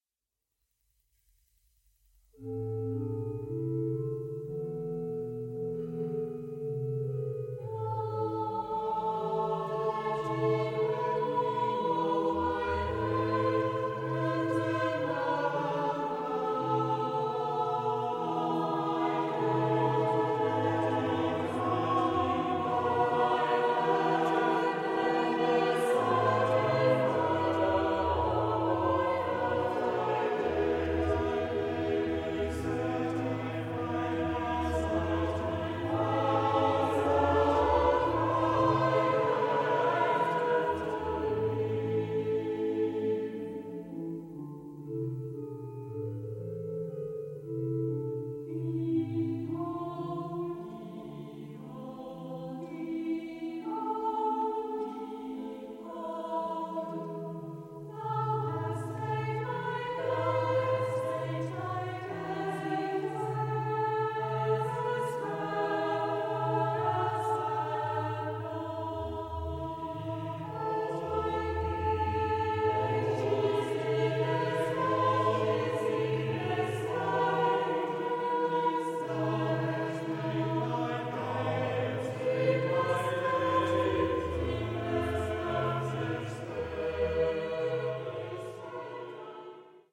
SATB (4 voix mixtes) ; Partition complète.
Sacré ; Psaume ; Anthem Caractère de la pièce : large
Solistes : Sopranos (2)
Instruments : Orgue (1)
Tonalité : la mineur